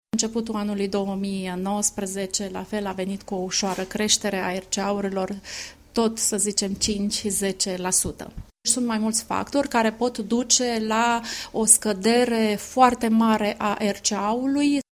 agent de asigurări